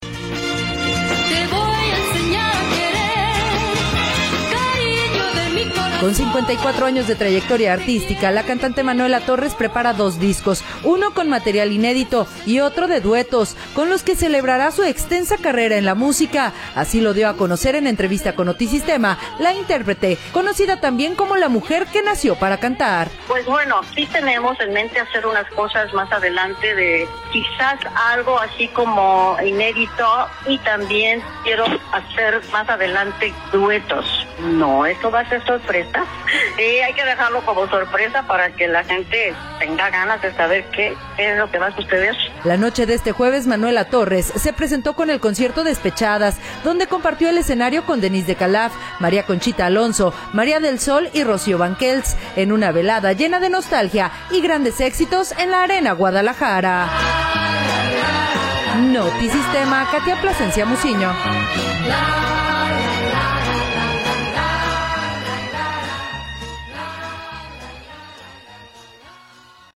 Así lo dio a conocer en entrevista con Notisistema la intérprete, […]